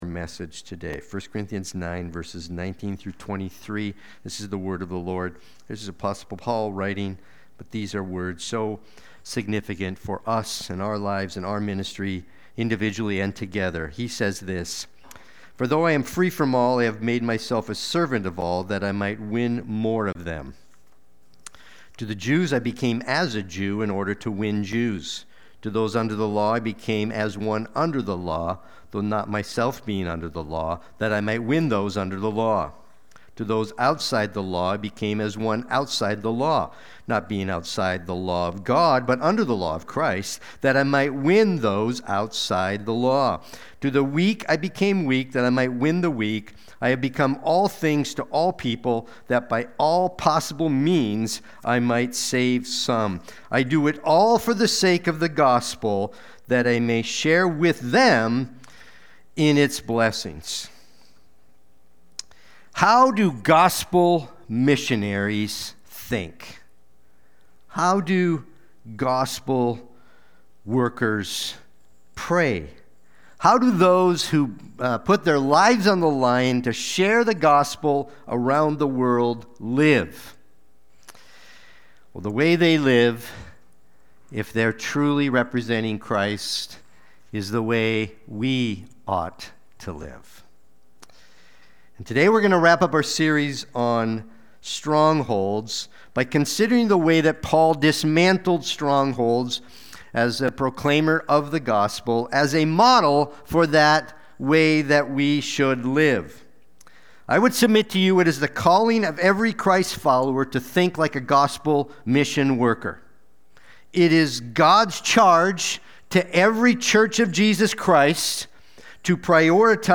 Sunday-Worship-main-2925.mp3